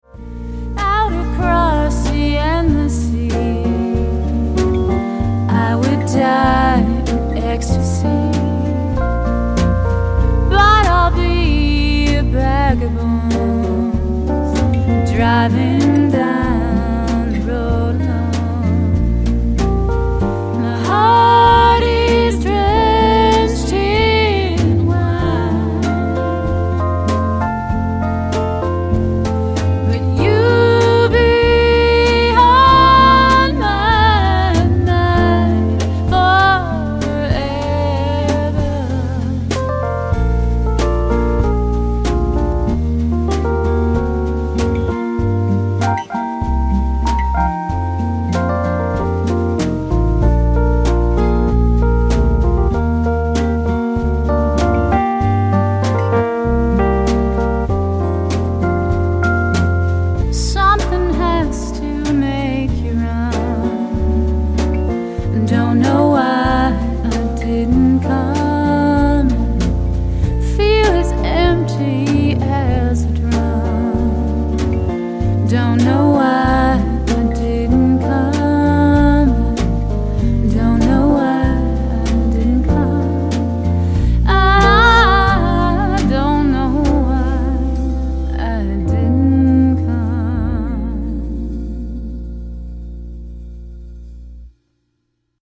keys/vocals
guitar
drums